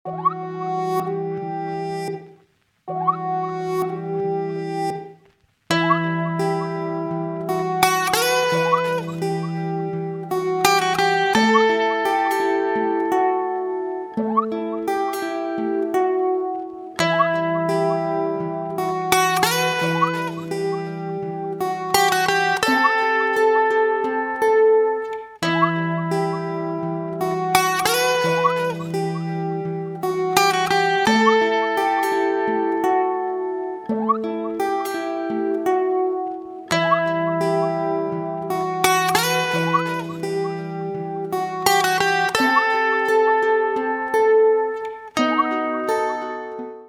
• Качество: 256, Stereo
гитара
красивые
грустные
без слов